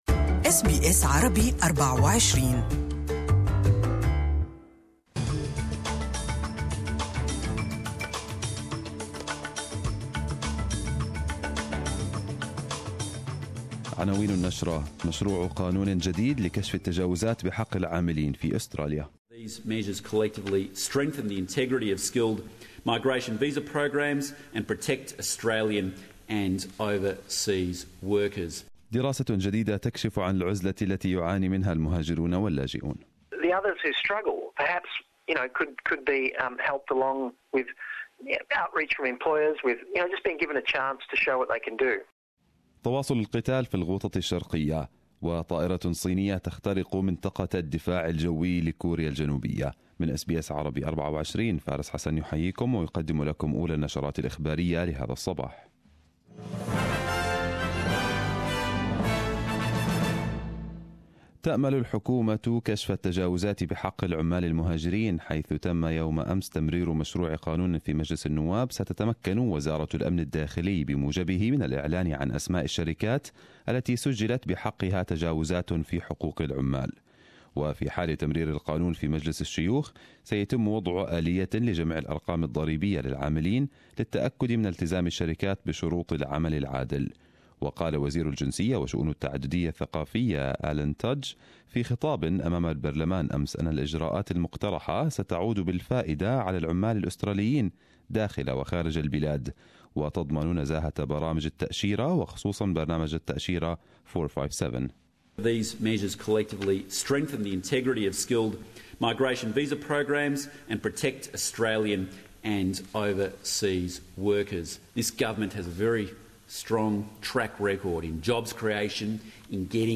Arabic News Bulletin 28/02/2018